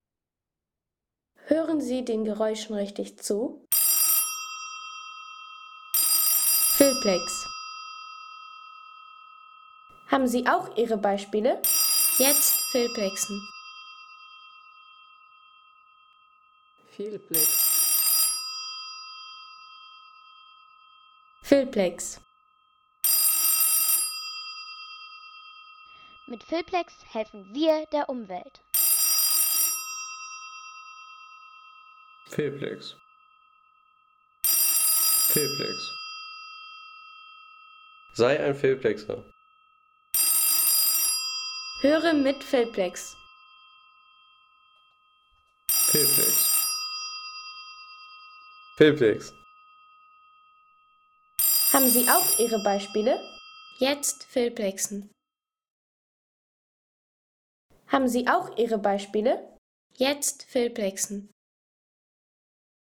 Klingelton Siemens W28